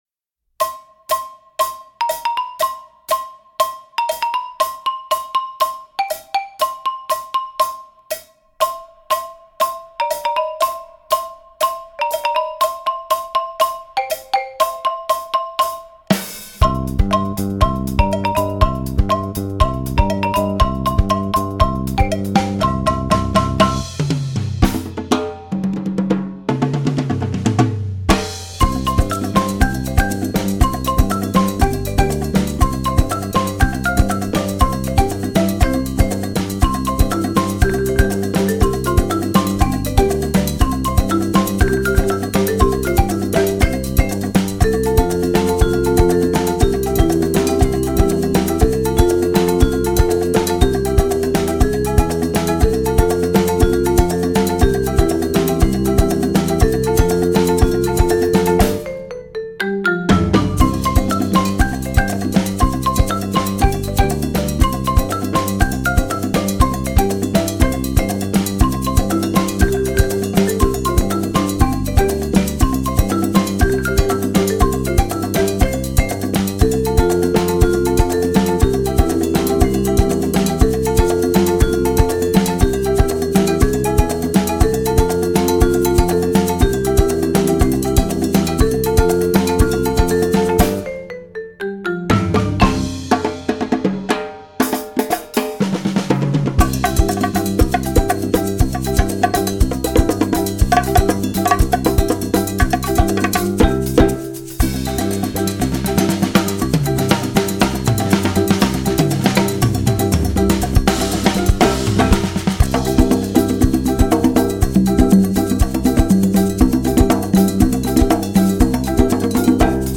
Voicing: Percussion Ensemble